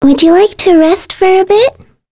Mirianne-Vox_Skill4.wav